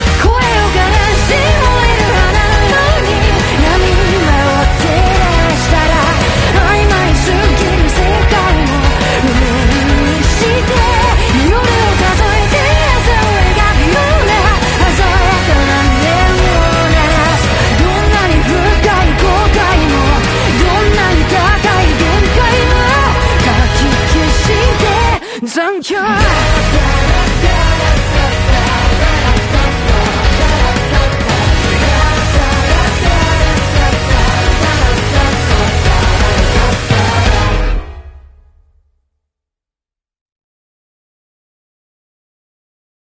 (AI Cover)